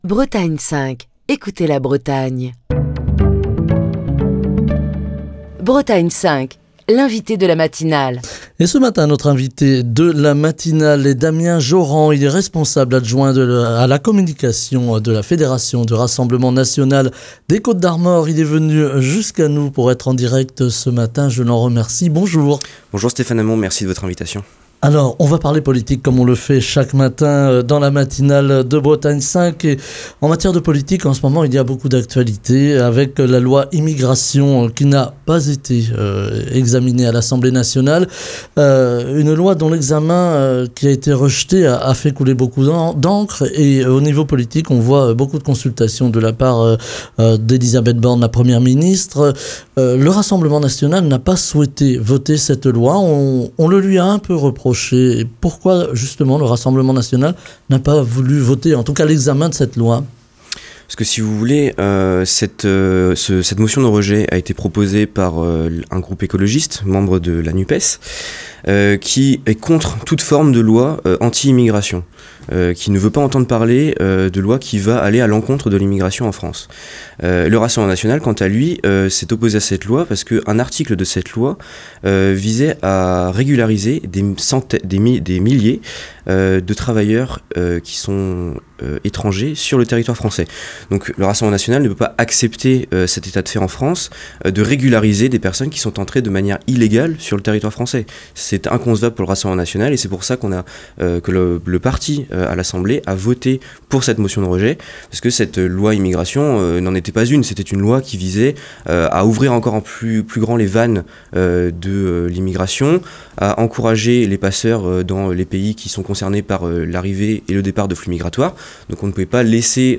invité politique de Bretagne 5 Matin